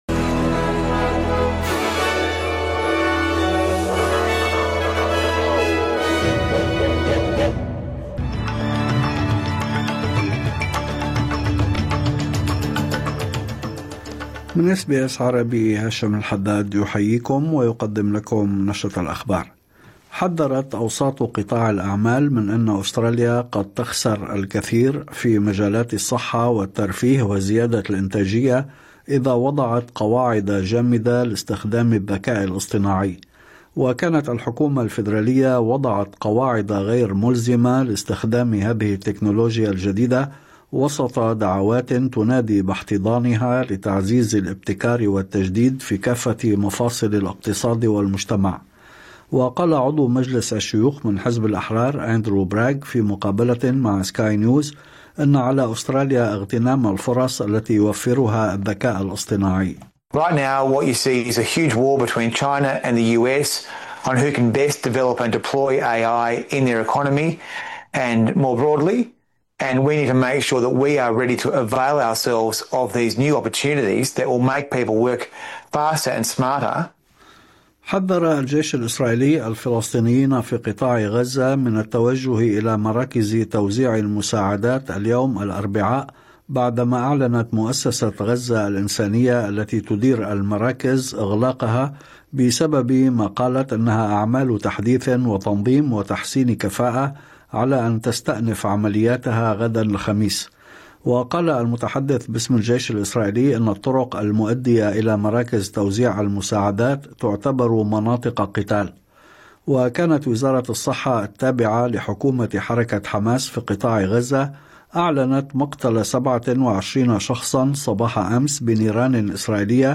نشرة أخبار الظهيرة 4/6/2025